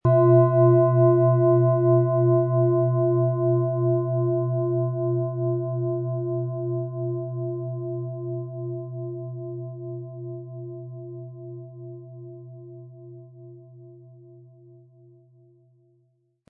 Von erfahrenen Fachkräften in einem indischen Dorf wurde diese Delfin Klangschale von Hand hergestellt.
• Tiefster Ton: Platonisches Jahr
Den passenden Schlegel erhalten Sie kostenfrei mitgeliefert, der Schlägel lässt die Schale voll und wohltuend erklingen.
Gehen Sie bitte zum Klangbeispiel, dort hören Sie den Ton von genau dieser Schale.
MaterialBronze